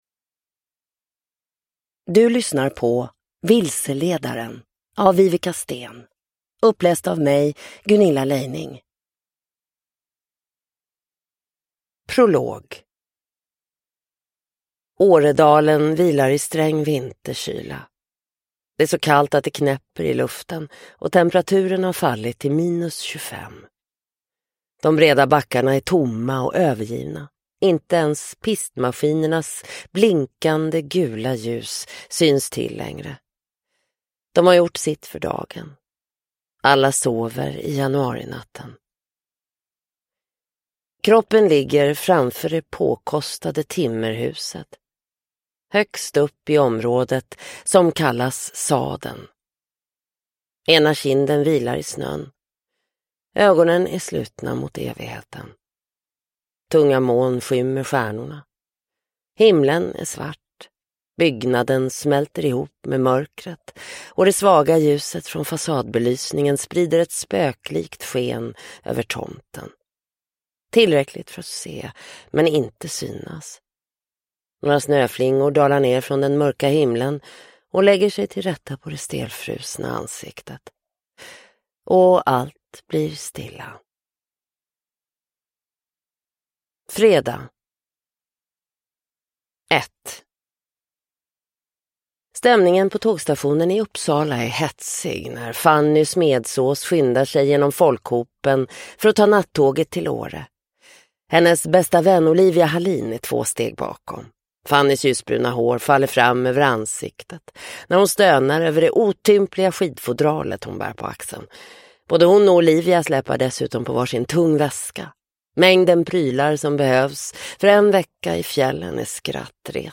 Vilseledaren – Ljudbok